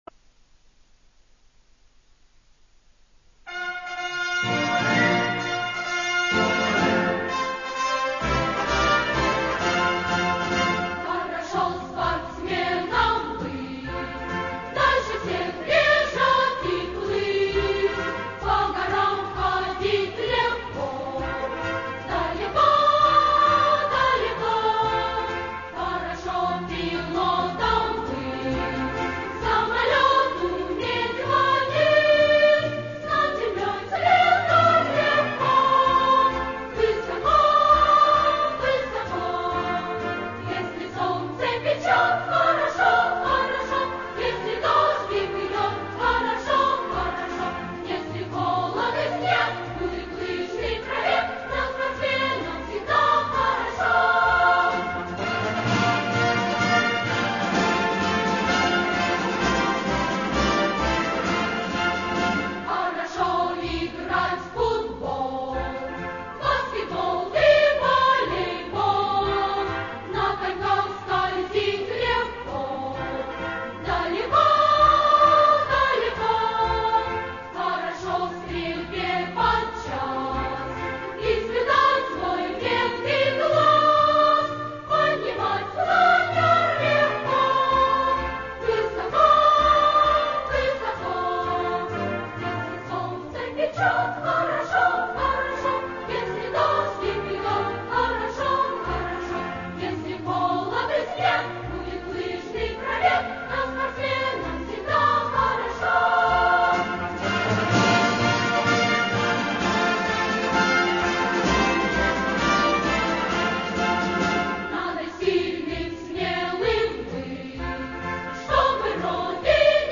Добрая ласковая песня об увлечении спортом.